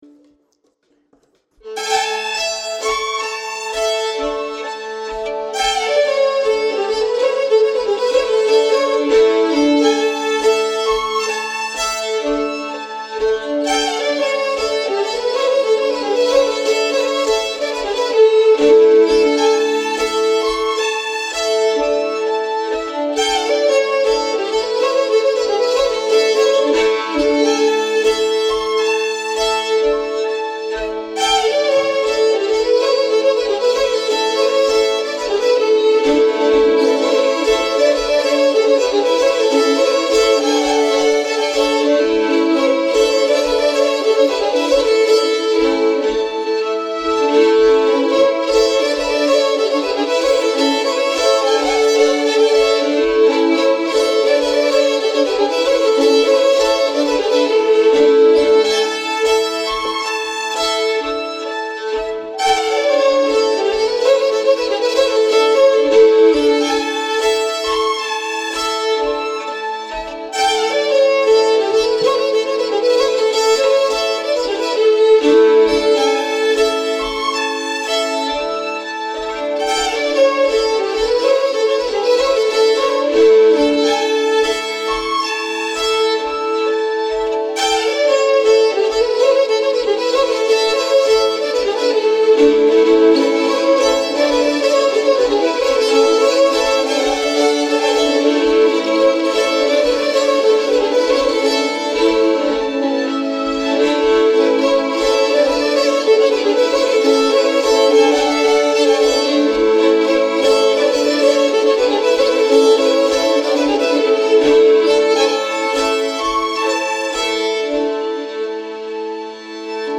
Riksspelman på Svensk Säckpipa
Jag drog igång dansen efter spelmanslaget.